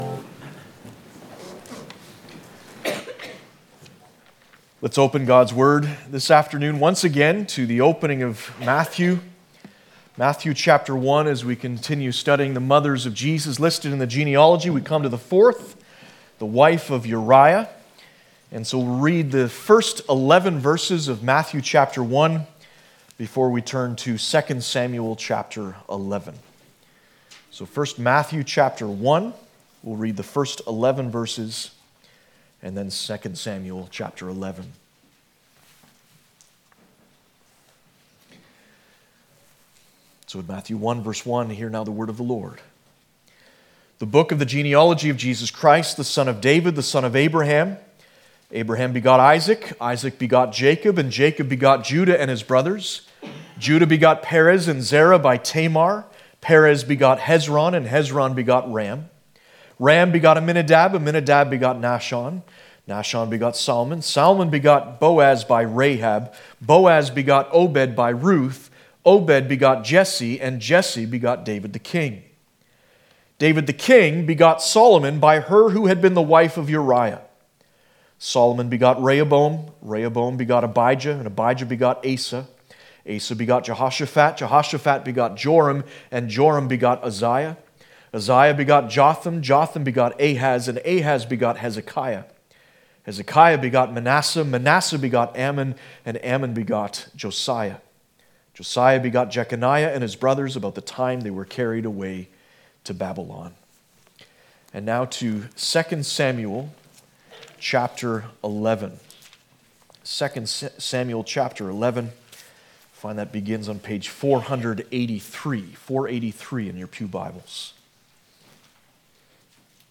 Passage: 1 Samuel 11 Service Type: Sunday Afternoon